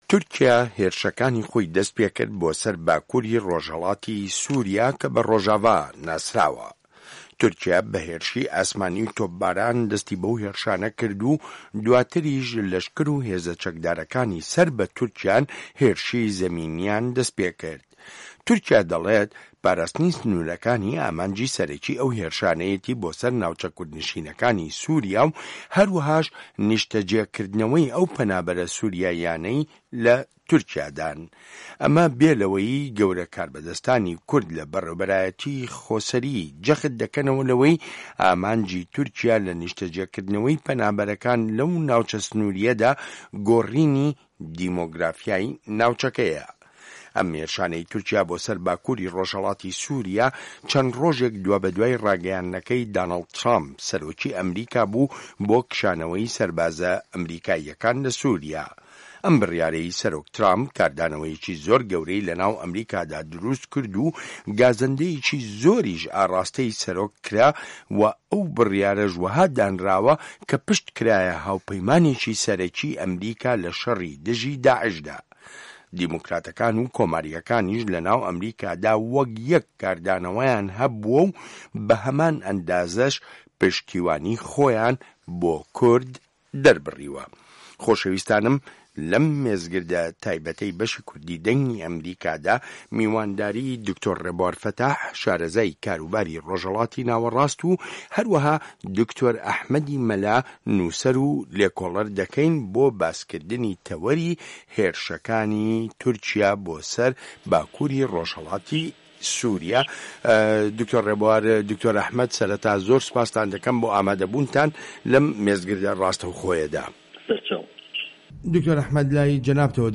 مێزگرد: کردە لەشکرییەکانی کانی ئاشتی لە پایزێکی پڕ لە کزەدا